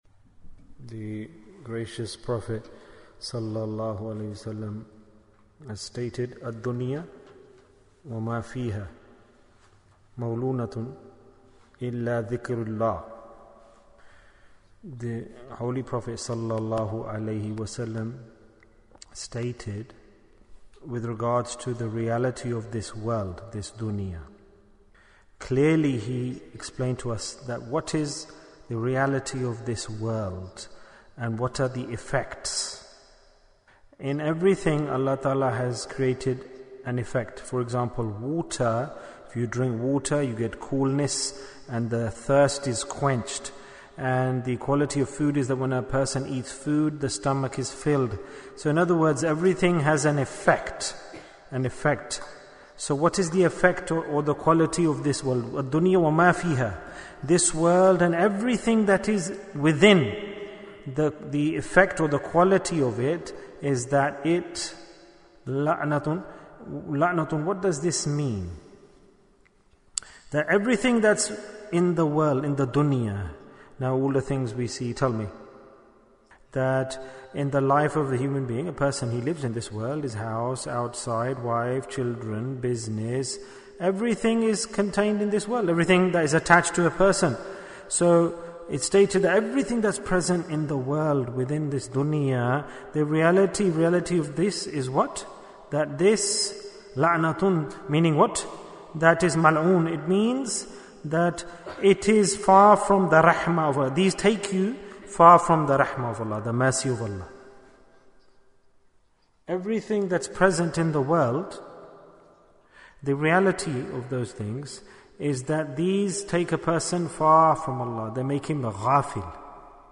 It Can Be Easy to Practice Deen Bayan, 28 minutes17th June, 2021